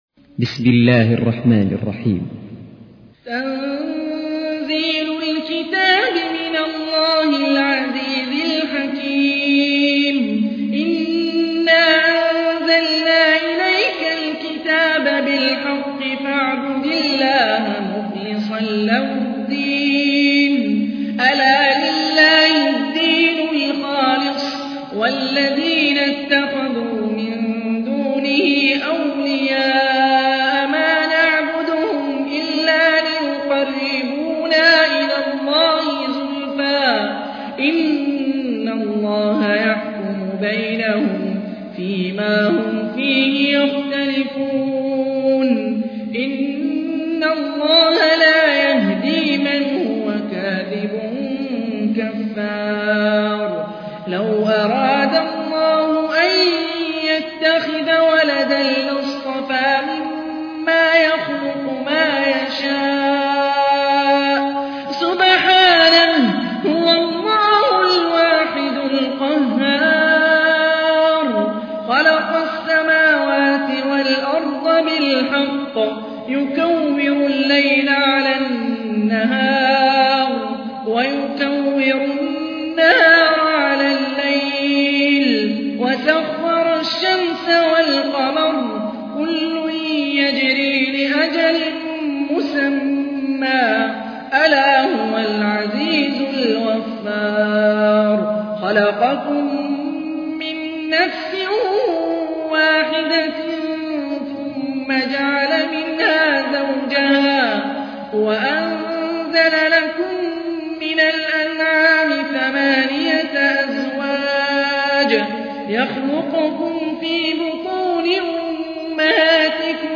تحميل : 39. سورة الزمر / القارئ هاني الرفاعي / القرآن الكريم / موقع يا حسين